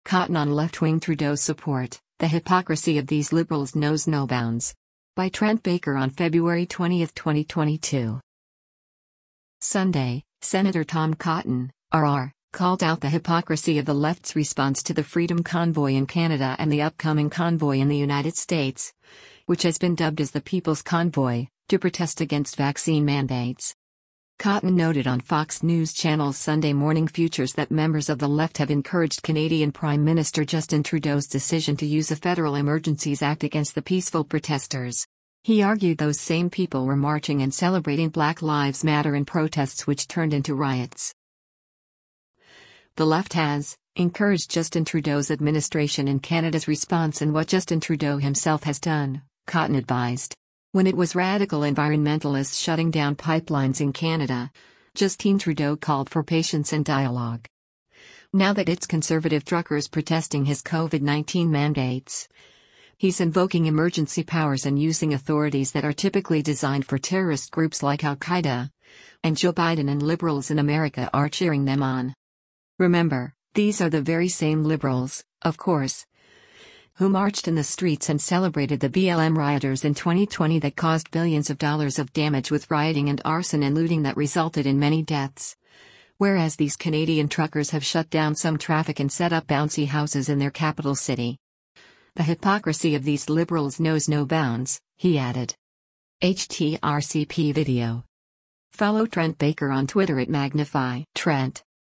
Cotton noted on Fox News Channel’s “Sunday Morning Futures” that members of the left have “encouraged” Canadian Prime Minister Justin Trudeau’s decision to use a federal Emergencies Act against the peaceful protesters.